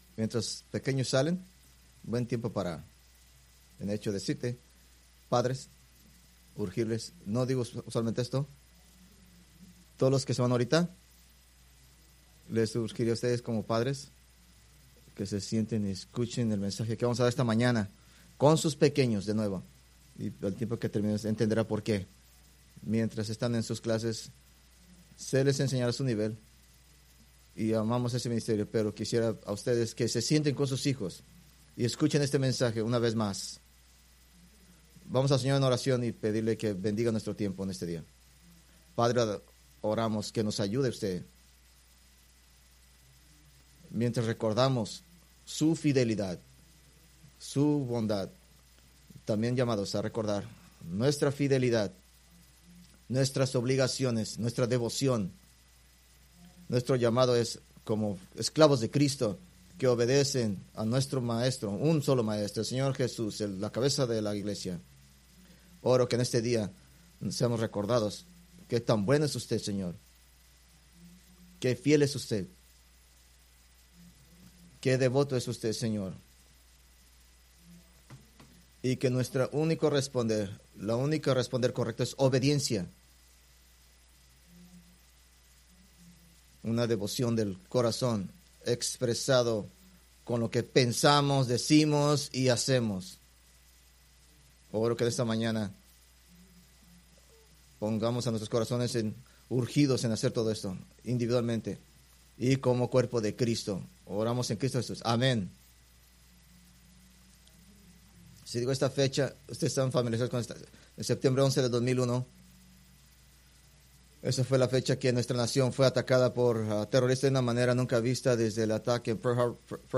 Preached March 16, 2025 from Escrituras seleccionadas